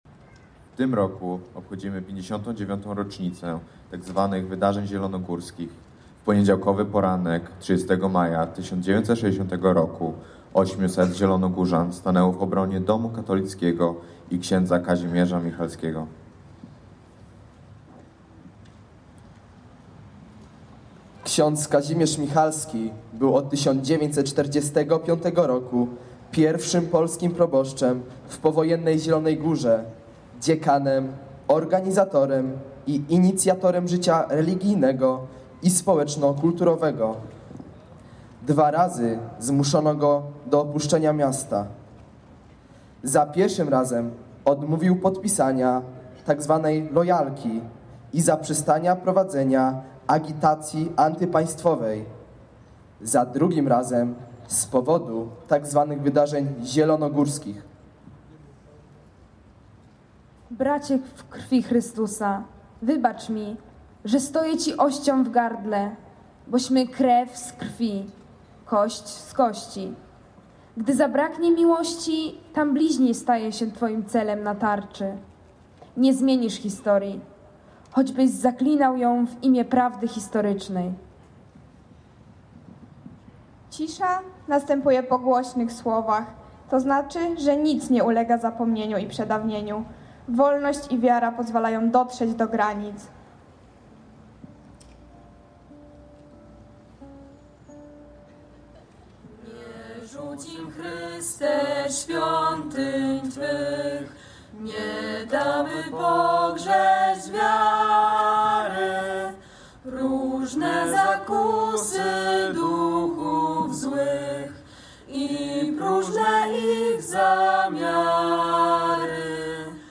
wydarzenia-czesc-artystyczna.mp3